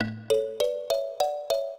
minuet5-4.wav